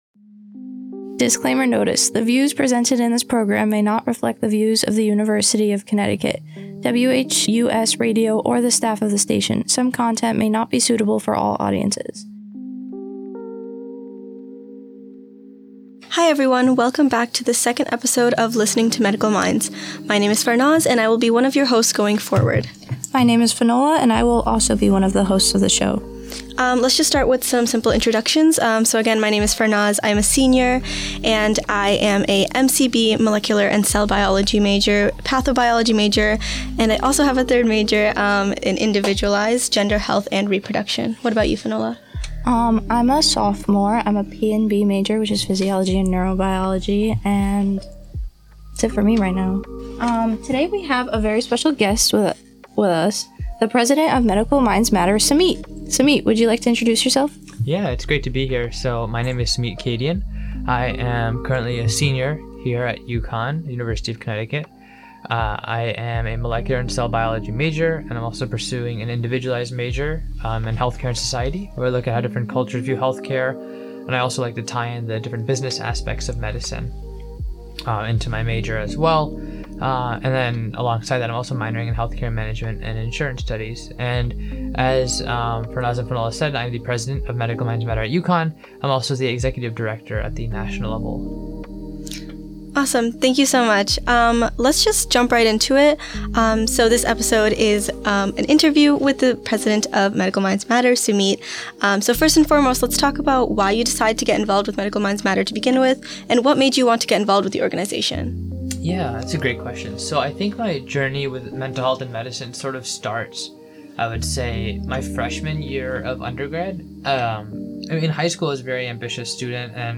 An Interview with the President